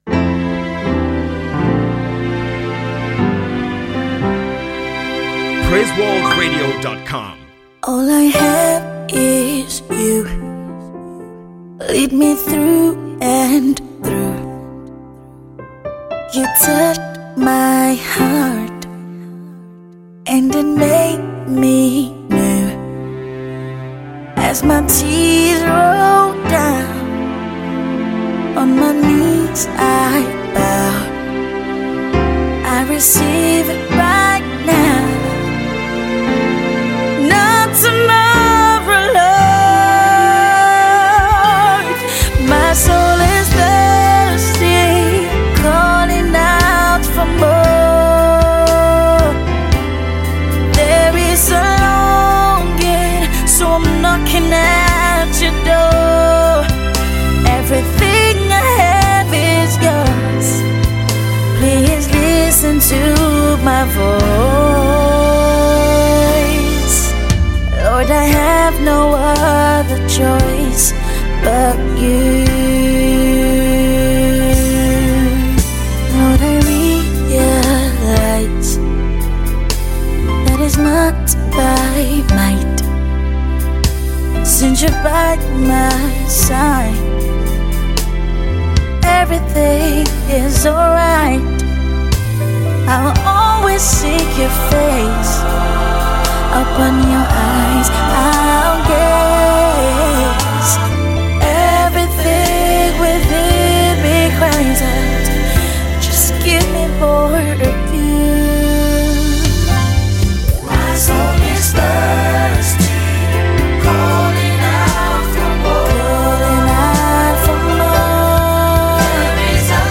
Endowed with a soothing vocal texture
worship song